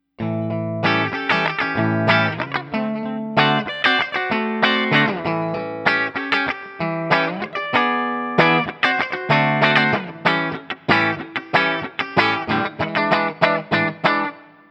This guitar has a very bright Stratty-like tone that’s made powerful through the EMG humbucker.
7th Chords
I recorded this guitar using my Axe-FX II XL+, direct into my Macbook Pro using Audacity.
Since there is only one pickup and thus no pickup selector switch, the recordings are each of the one pickup with the knobs on 10.